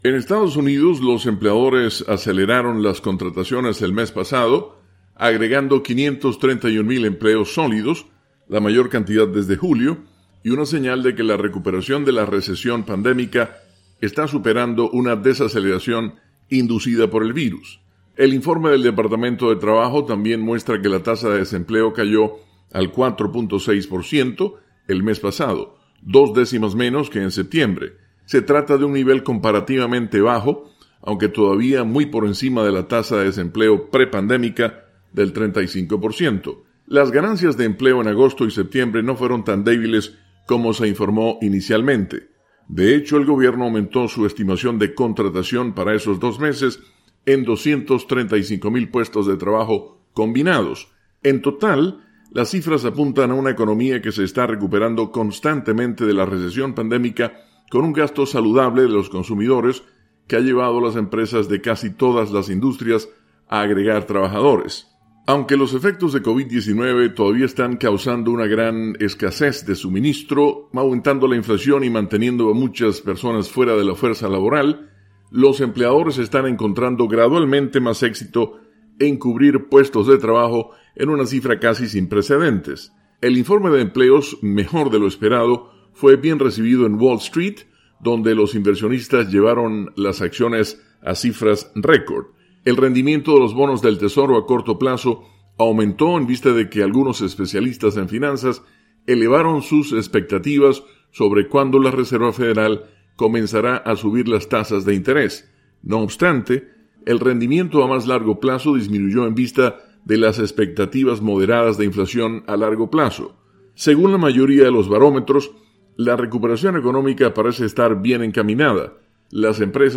desde los estudios de la Voz de América, en Washington.